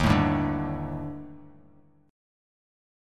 D#M7b5 chord